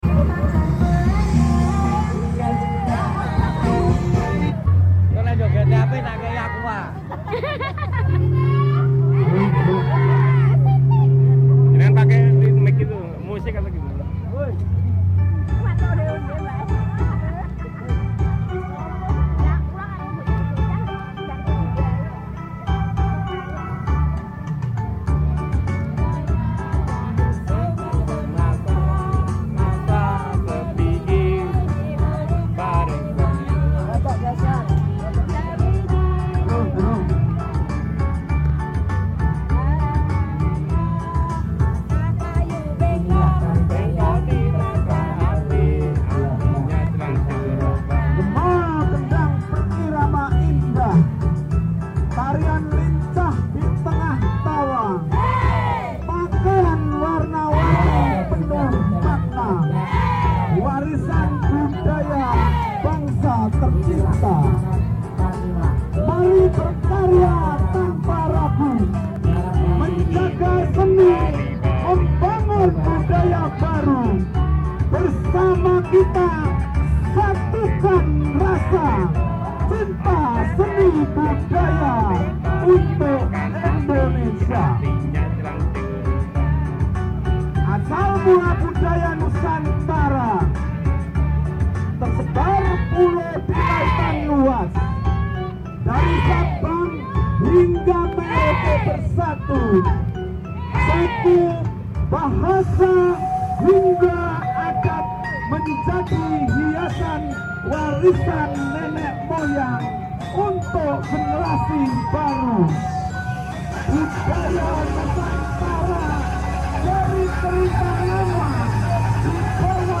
Penampilan Yel Yel Dan Tarian Sound Effects Free Download